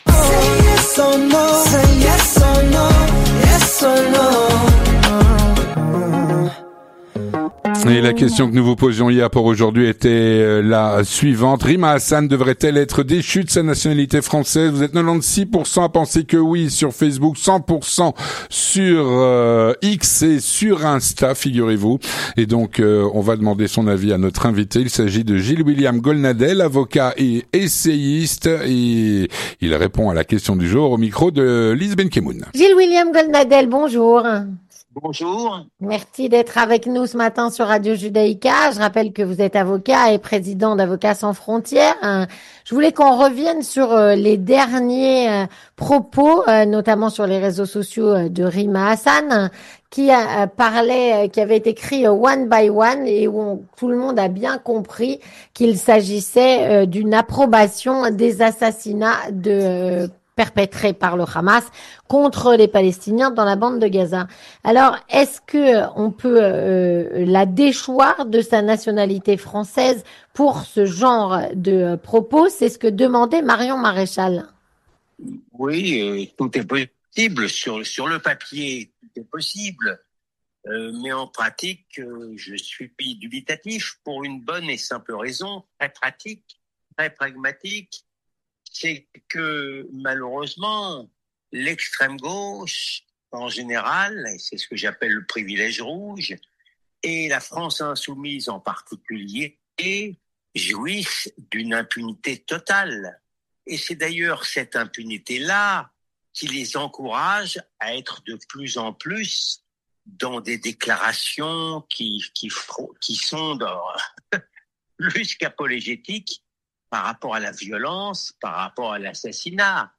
Gilles-William Goldnadel, Avocat, essayiste et chroniqueur sur CNews, répond à la "Question Du Jour".